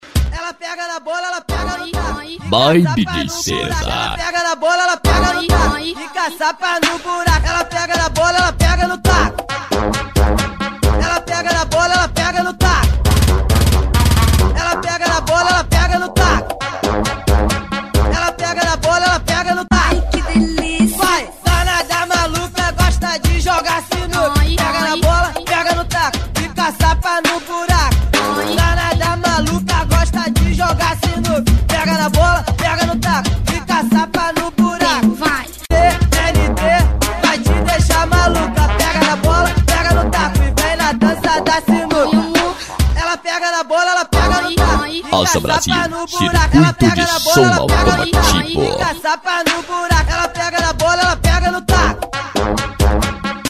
Funk
Funk Nejo